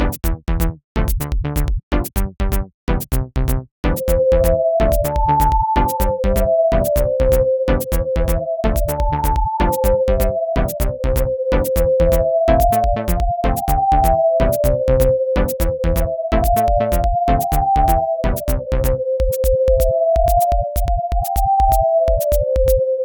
botjazz.ogg